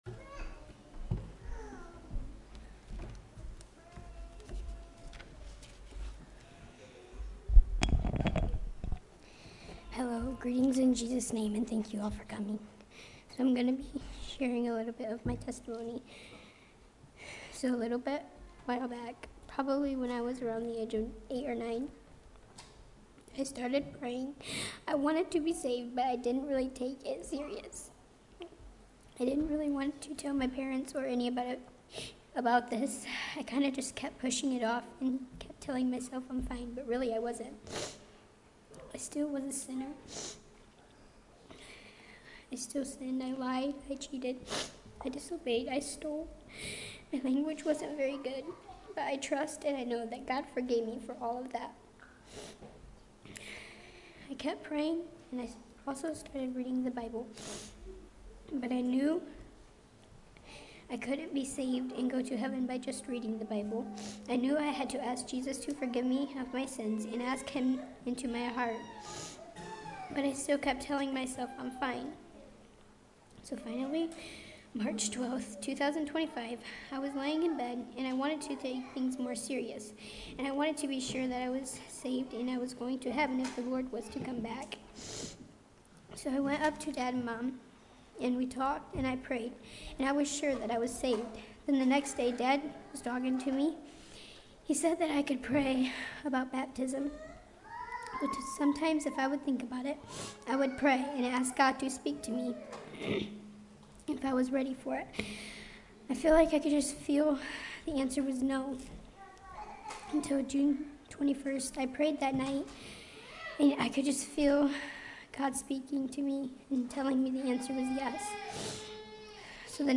Baptismal Testimony